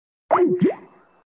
1s_Skype end
1s-skype-end.mp3